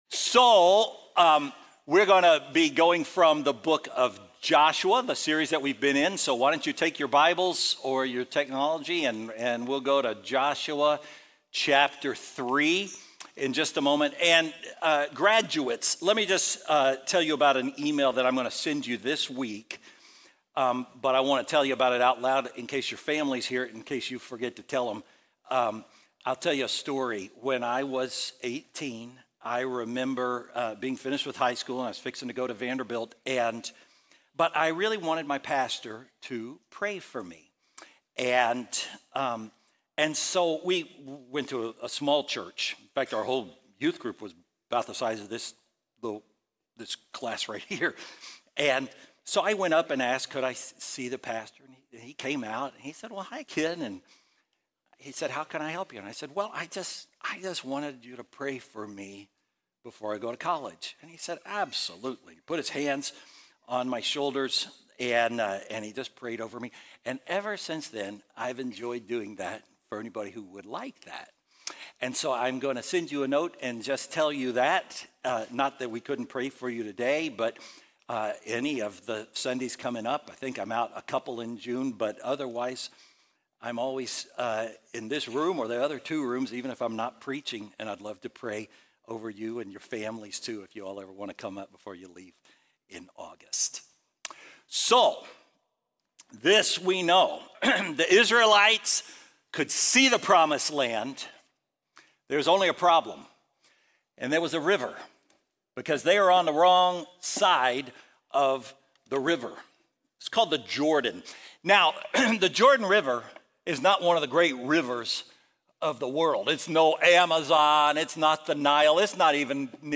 Faithbridge Sermons Three Stones to Carry with You May 18 2025 | 00:30:57 1x 00:00 / 00:30:57 Subscribe Share Apple Podcasts Spotify Overcast RSS Feed Share Link Embed